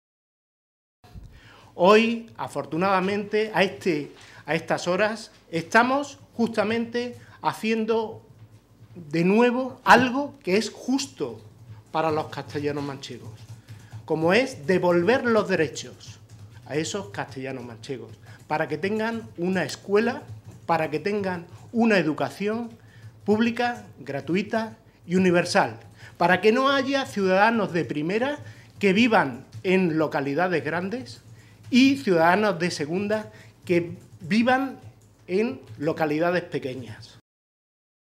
El diputado regional del PSOE y portavoz de Educación en las cortes de Castilla-La Mancha, Fausto Marín mostró “su satisfacción porque en este momento se está abriendo la primera escuela rural, la de Alcoroches, en Guadalajara”.
Cortes de audio de la rueda de prensa